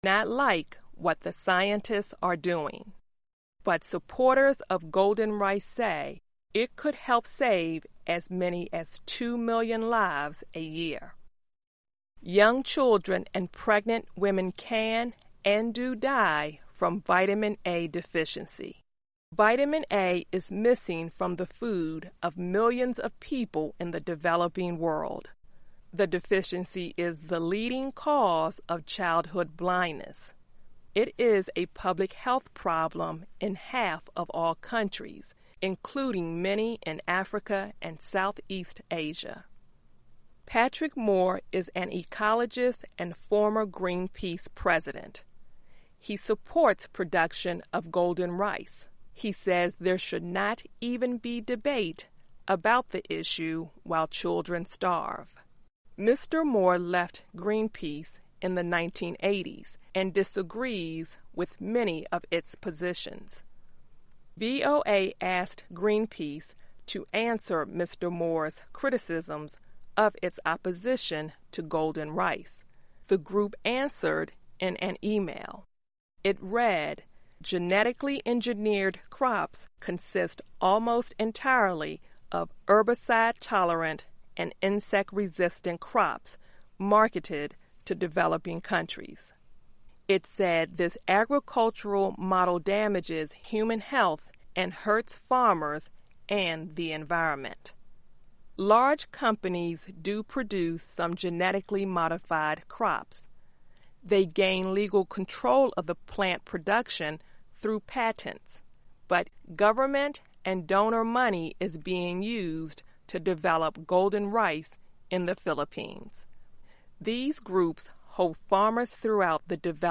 Learning English as you listen to a weekly show about the environment, science, farming, food security, gardening and other subjects. Our daily stories are written at the intermediate and upper-beginner level and are read one-third slower than regular VOA English.